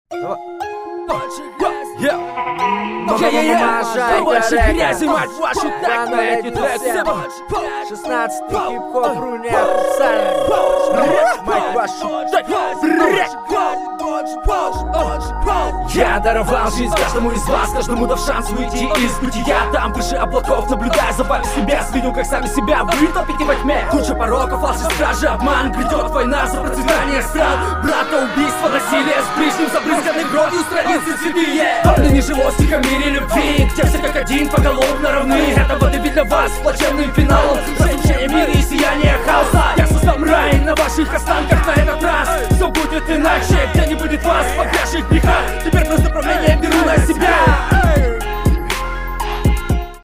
Понравилось, как обыграны бэки
Часто не попадешь в бит, местами заговариваешься и перебираешь с хрипотцой